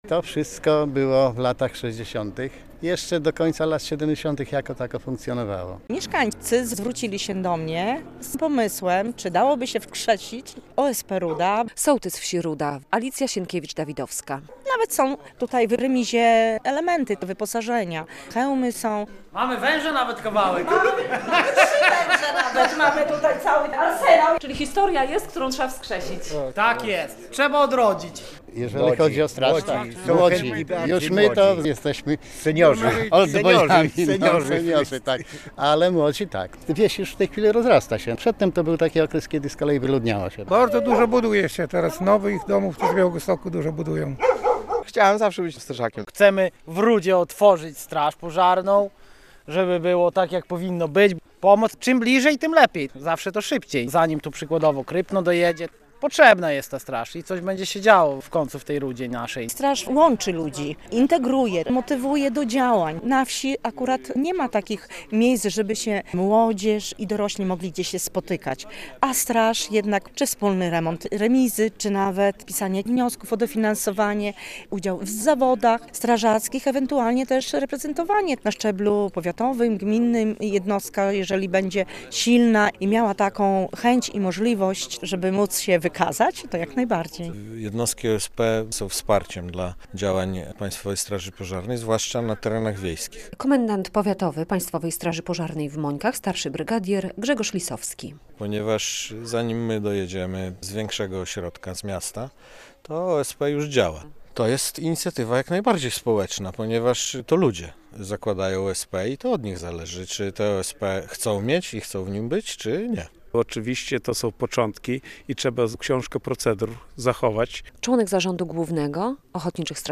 relacja
Jak mówi sołtys Alicja Sienkiewicz - Dawidowska: